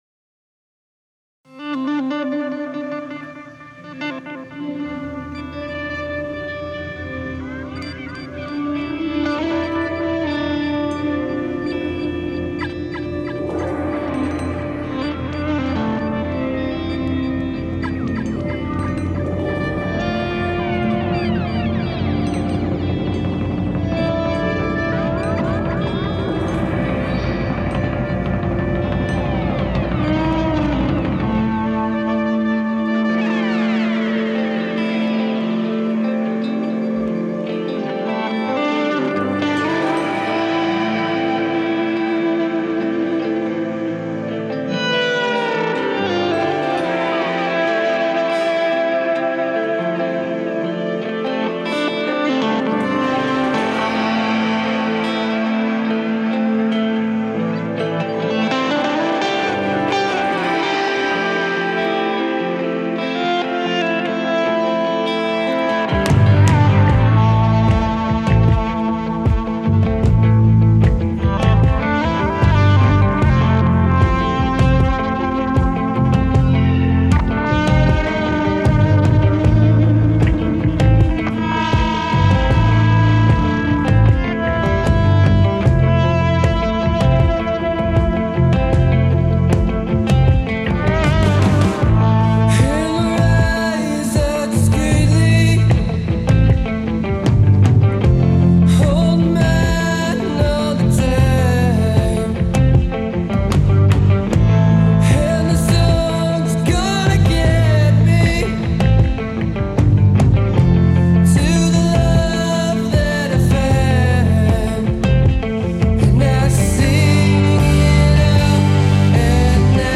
international alt rock band